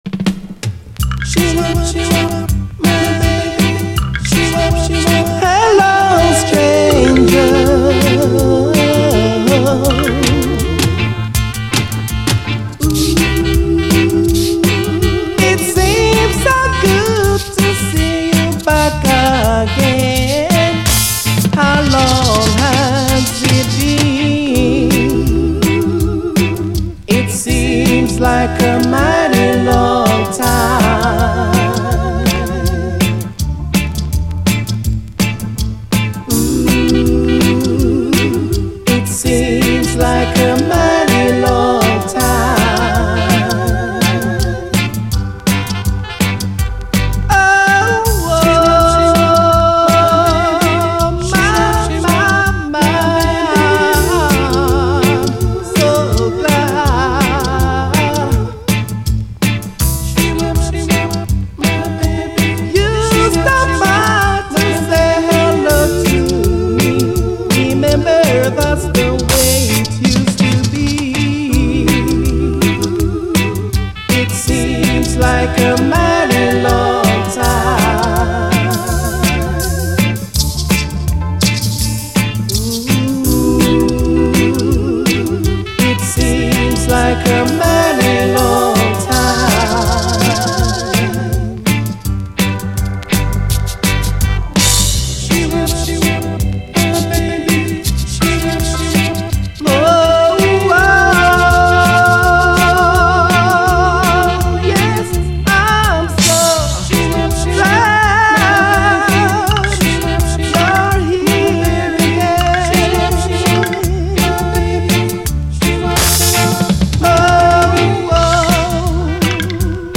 REGGAE
ヘタウマ系のイナタい歌声とイナタいリズムが味わい深い！